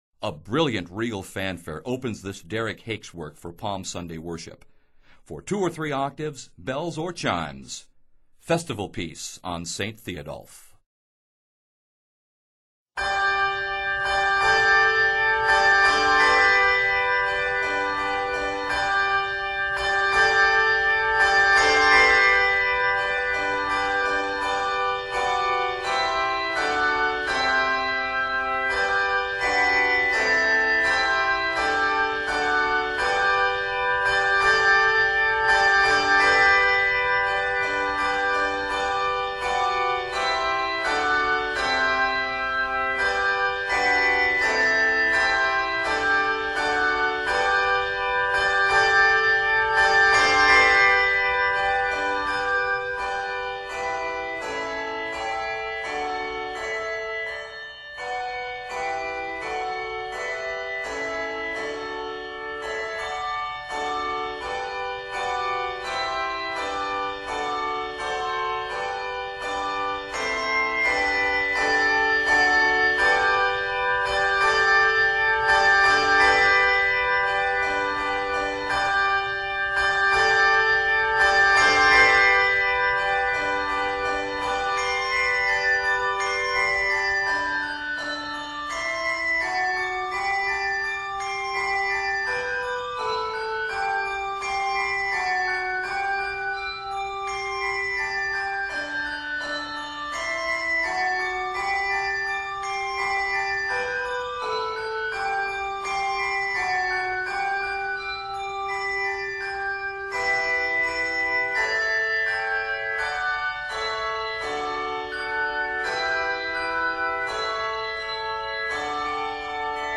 A total of 58 measures, it is arranged in G Major.